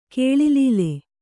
♪ kēḷilīle